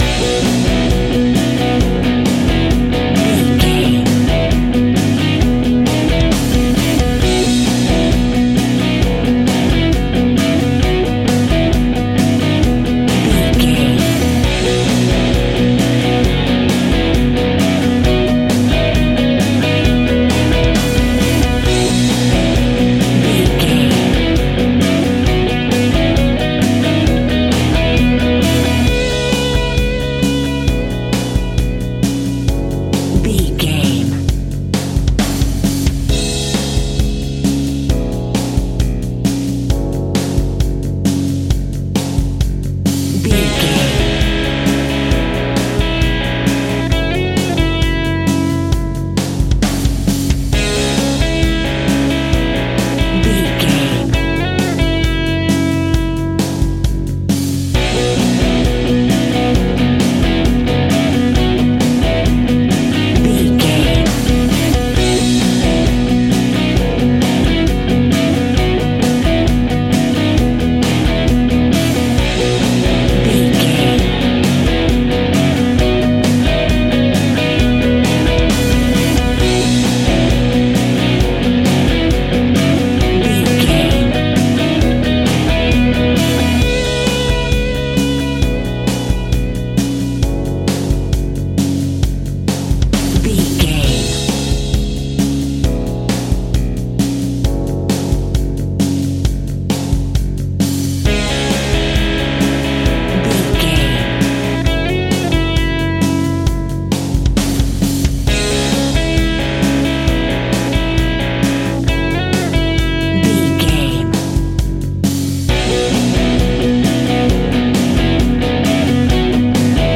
TV Reality Indie Rock Music.
Ionian/Major
indie pop
pop rock
sunshine pop music
drums
bass guitar
electric guitar
piano
hammond organ